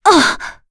Yuria-Vox_Damage_kr_02.wav